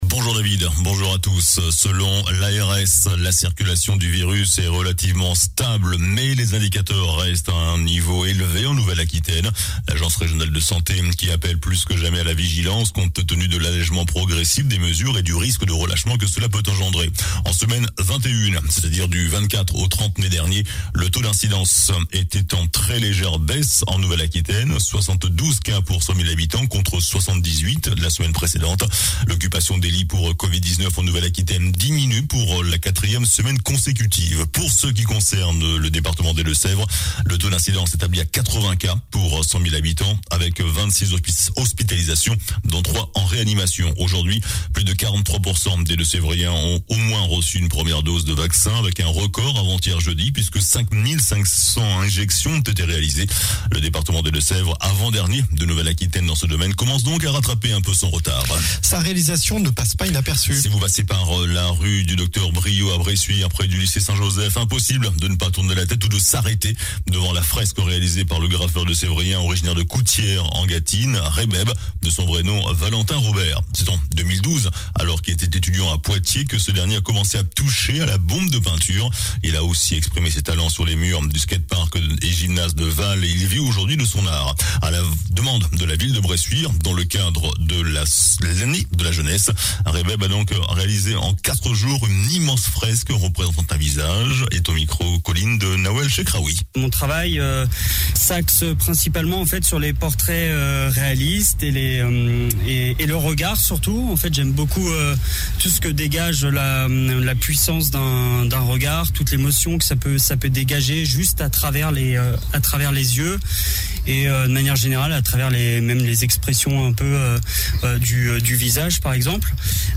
JOURNAL DU SAMEDI 05 JUIN